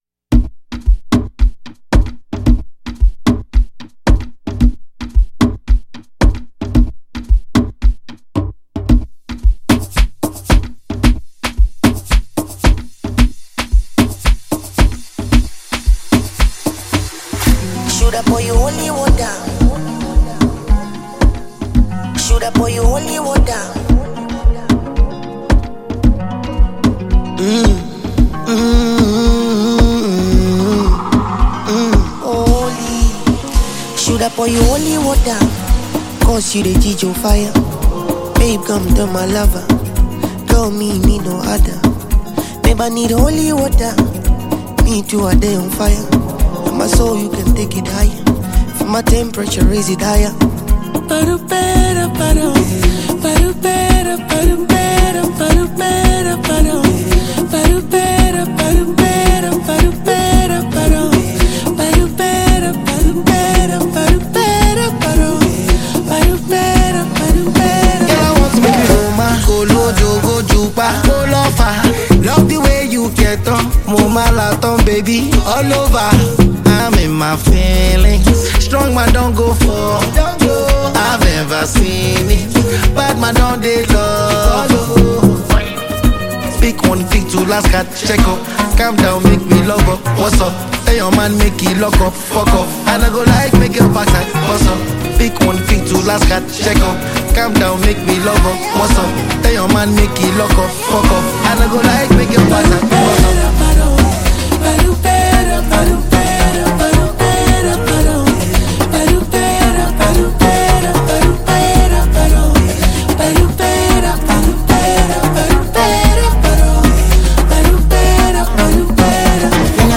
a wonderfully crafted afro-beat hit composition